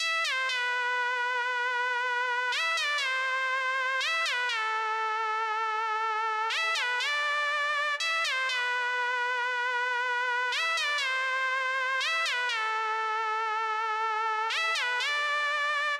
描述：Basic pitched lead
标签： 120 bpm Trap Loops Synth Loops 2.69 MB wav Key : Unknown
声道立体声